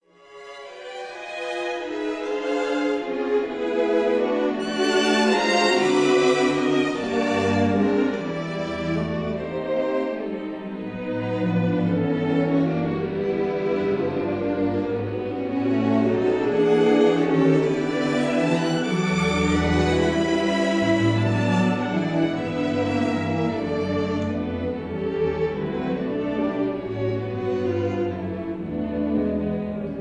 Recorded in No. 1 Studio, West Hampstead,